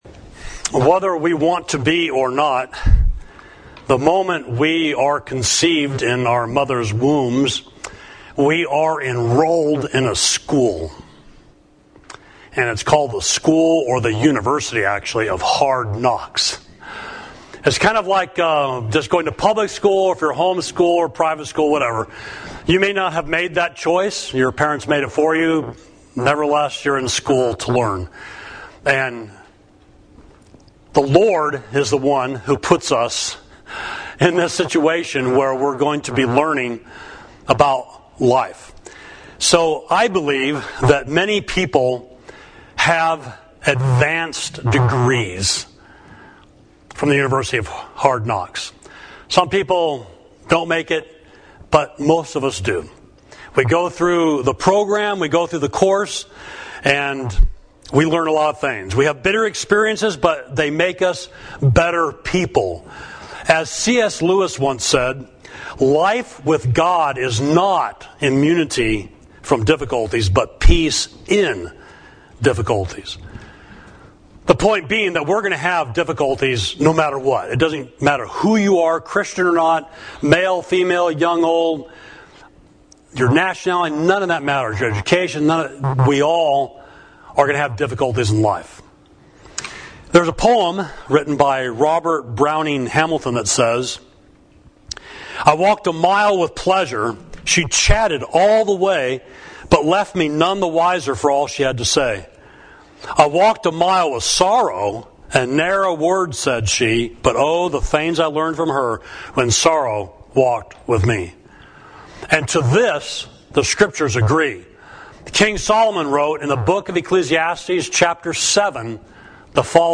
Sermon: The University of Hard Knocks